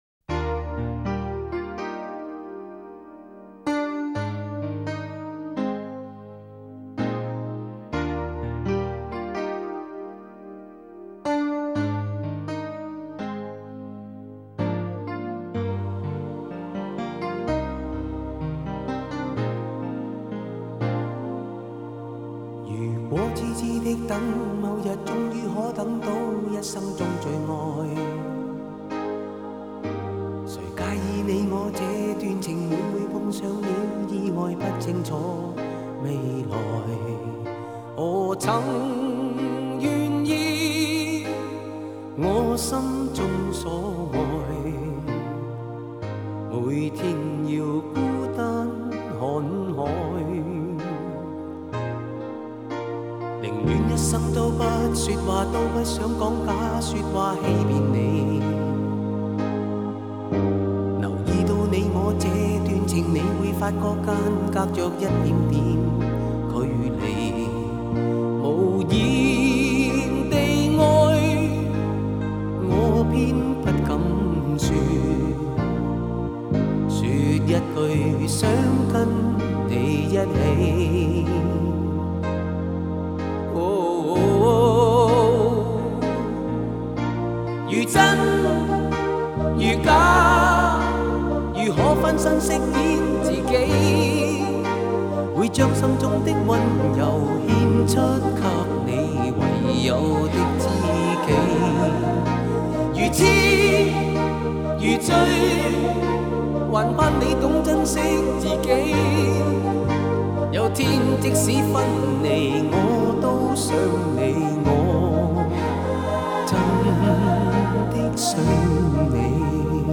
这首歌的旋律悠扬，歌词感人至深，唱出了对爱人深深的思念与真挚的爱。